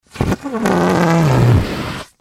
Balloon defecating